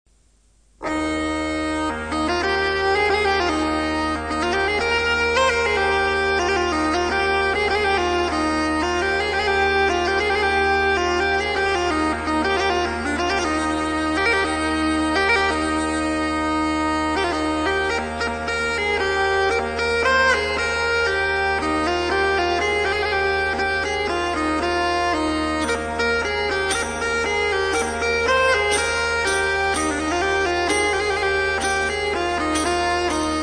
CORNEMUSE
Cornemuse traditionnelle
(chevrette)
ALLA FRANCESCA